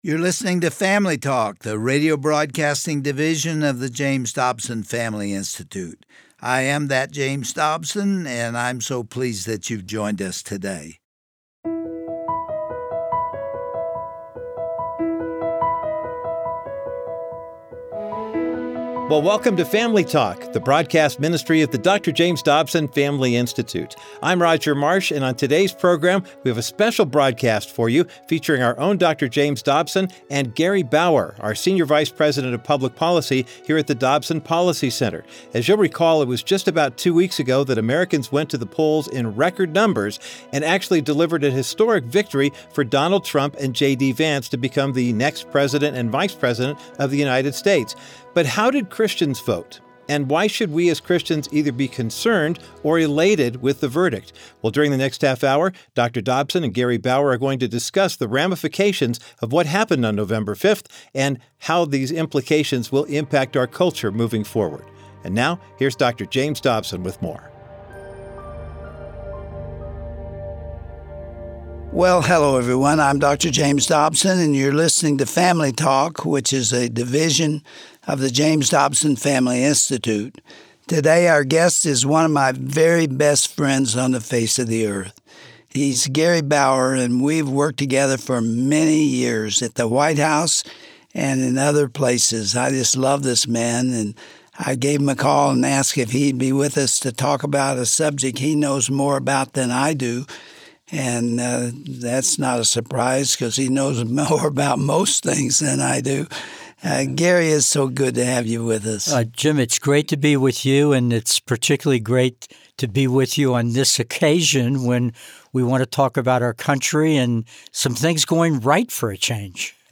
According to social researcher Dr. George Barna, 72 percent of Christians voted in this year’s election. On today’s edition of Family Talk, Dr. James Dobson and his guest, Gary Bauer, discuss Donald Trump’s historic win, and how Christians stepped up to promote righteousness in the public square.